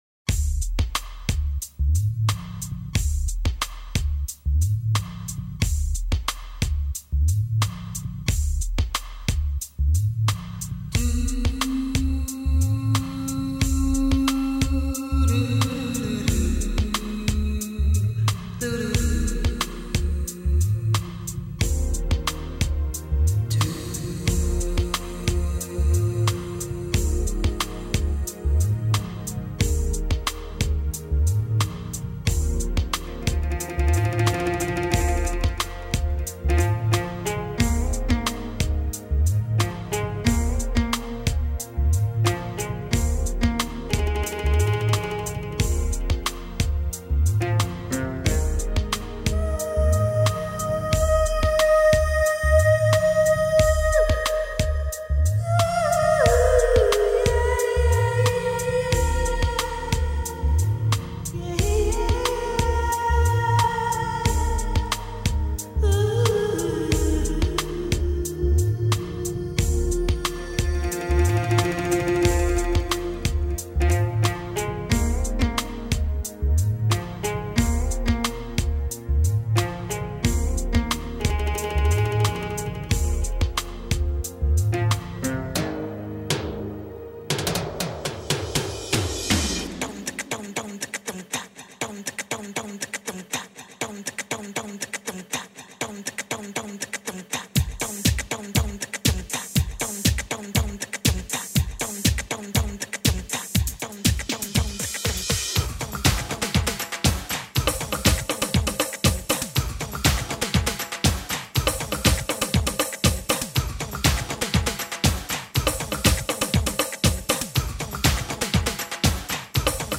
Singer : Instrumental